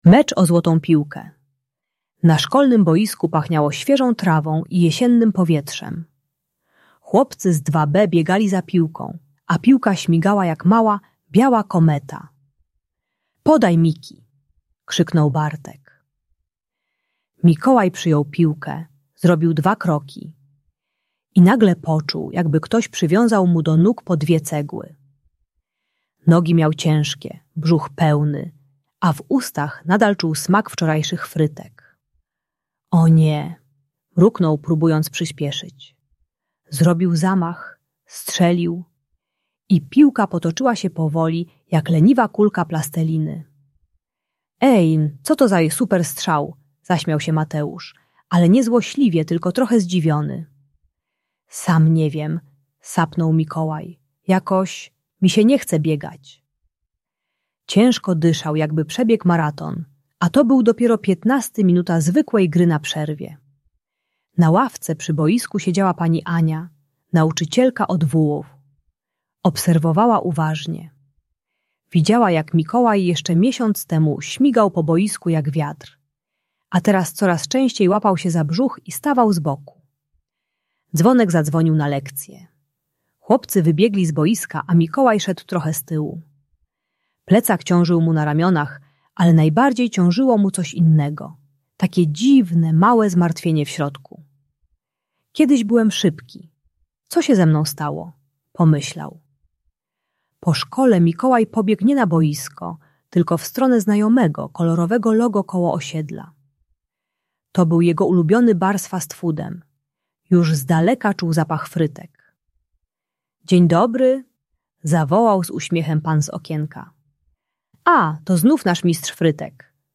Opowieść o Złotej Piłce - Problemy z jedzeniem | Audiobajka